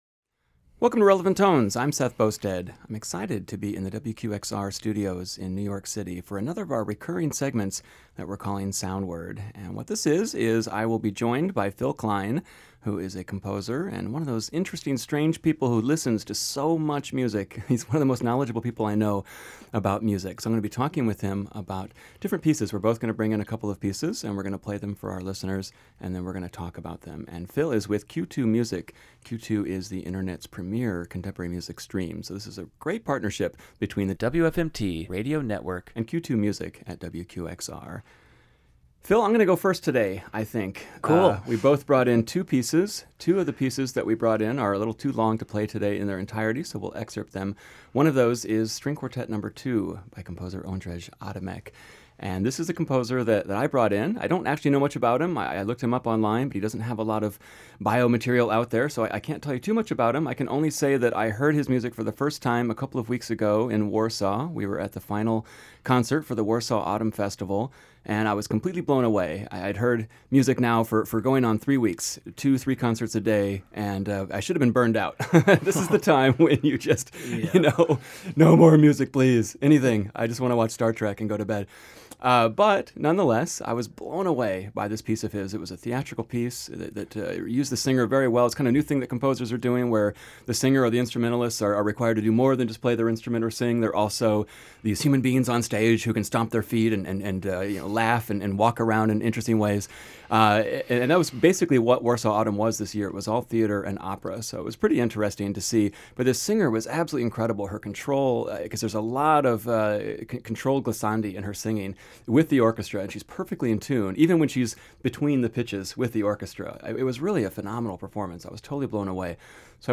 With each host bringing wildly different musical sensibilities to the table, Soundward is always lively, engaging and brimming with excitement for new music.